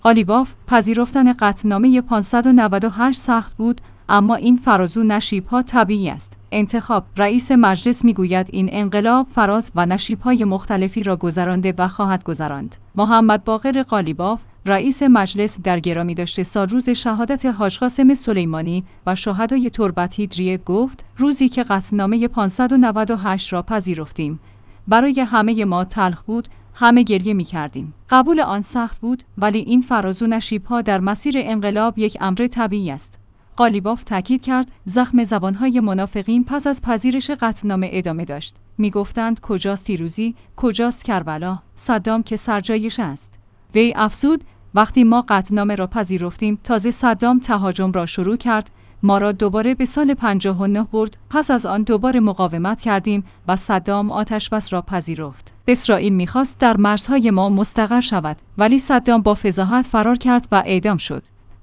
انتخاب/ رئیس مجلس می‌گوید این انقلاب فراز و نشیب های مختلفی را گذرانده و خواهد گذراند. محمدباقر قالیباف، رئیس مجلس در گرامیداشت سالروز شهادت حاج قاسم سلیمانی و شهدای تربت حیدریه، گفت: روزی که قطعنامه ۵۹۸ را پذیرفتیم، برای همه ما تلخ بود، همه گریه می‌کردیم.